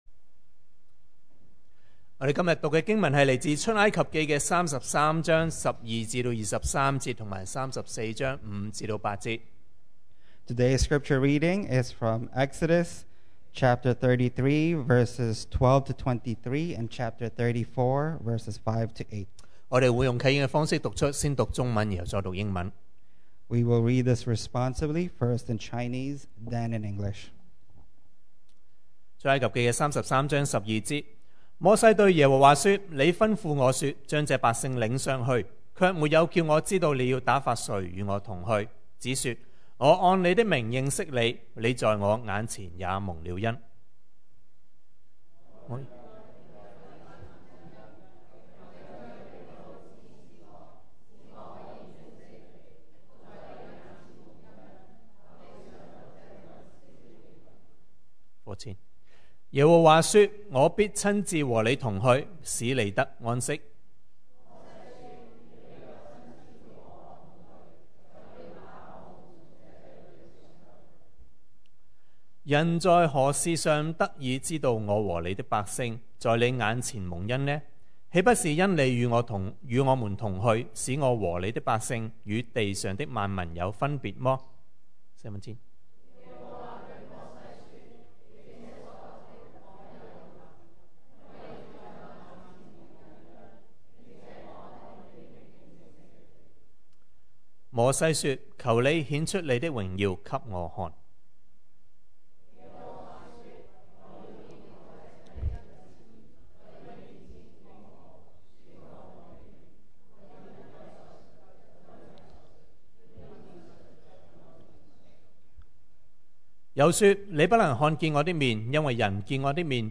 2020 sermon audios
Service Type: Sunday Morning